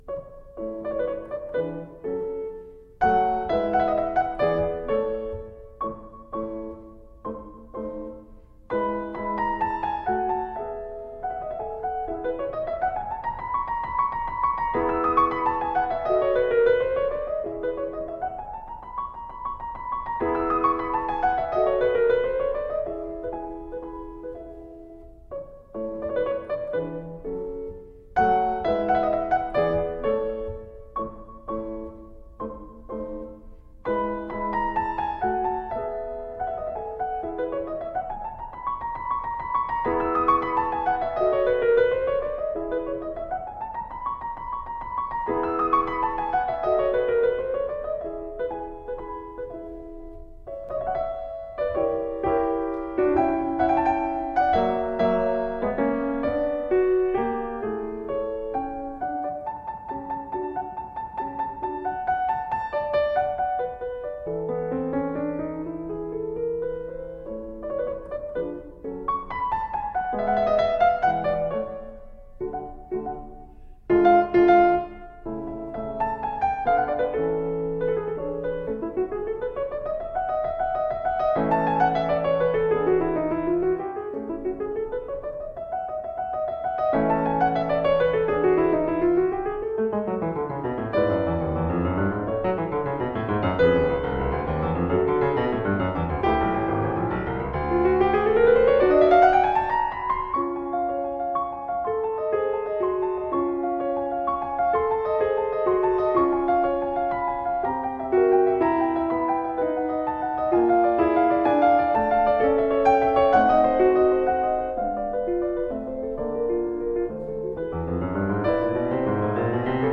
DESCRIPTION OF THE PIECE: a lovely single-movement piano piece in rondo form.
The C portion of this rondo form features sixteenth-note triplets and can be subdivided as e-e-f-eˈ, again, with the last e section (“e prime”) extended.
Rondo C